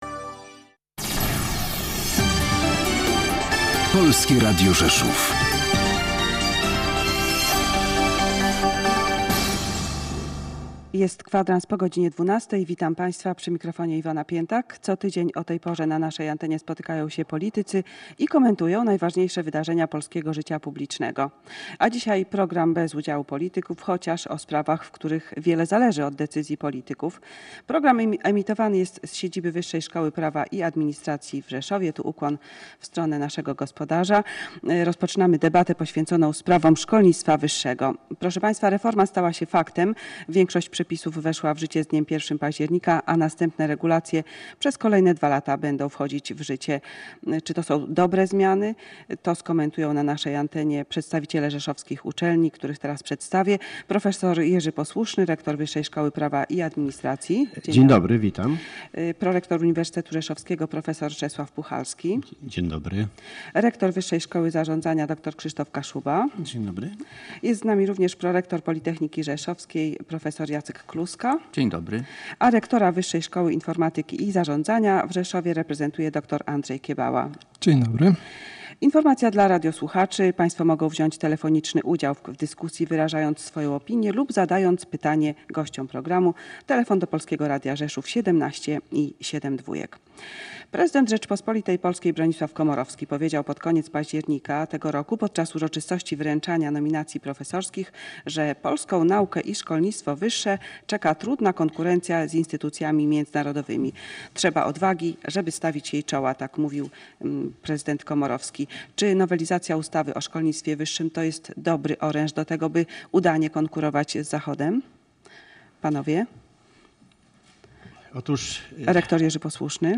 Debata.mp3